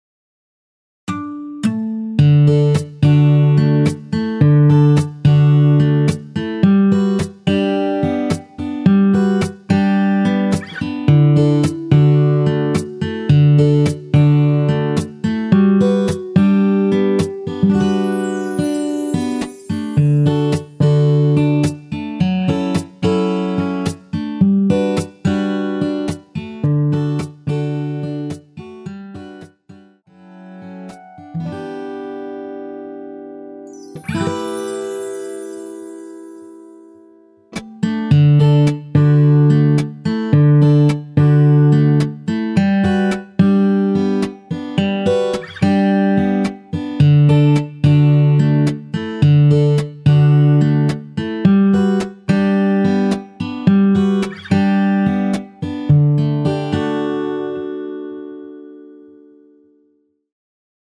원키에서(+7)올린 MR입니다.
엔딩이 페이드 아웃이라 마지막 음~ 2번 하고 엔딩을 만들어 놓았습니다.(미리듣기 참조)
앞부분30초, 뒷부분30초씩 편집해서 올려 드리고 있습니다.